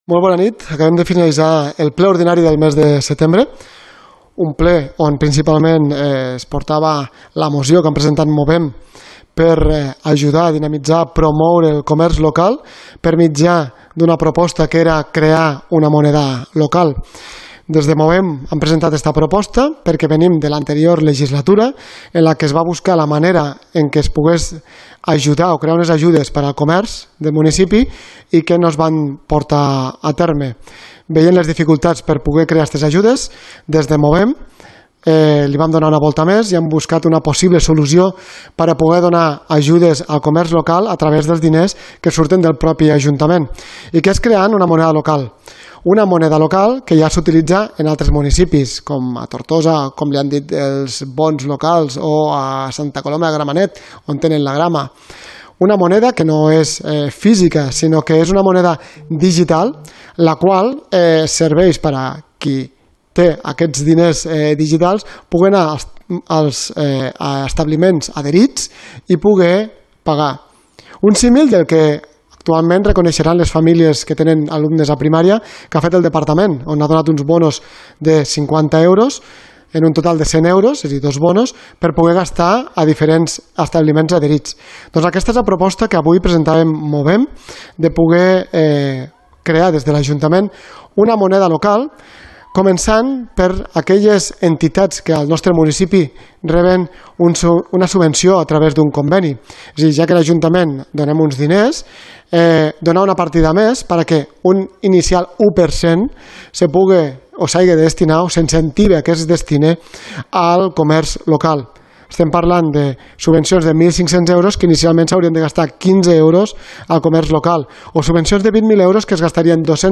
Ple Ordinari de Roquetes – Setembre 2023 – Declaracions – Movem Roquetes – David Poy.